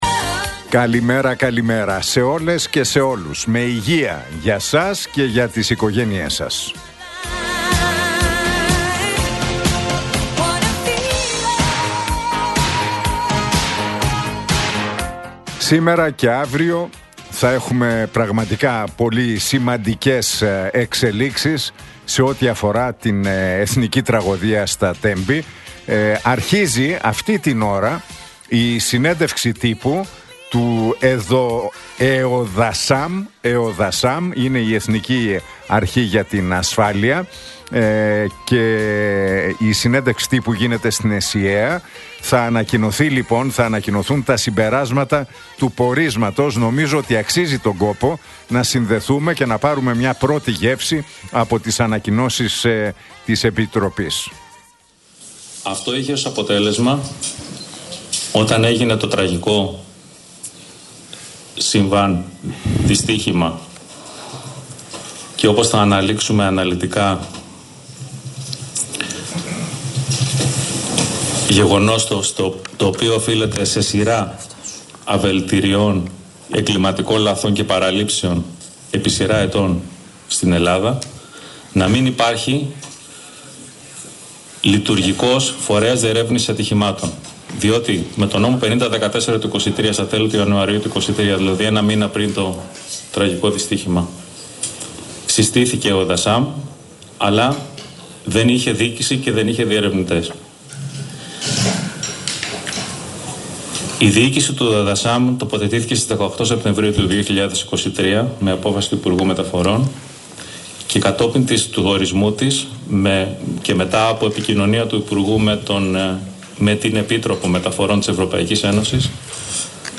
Ακούστε το σχόλιο του Νίκου Χατζηνικολάου στον ραδιοφωνικό σταθμό RealFm 97,8, την Πέμπτη 27 Φεβρουαρίου 2025.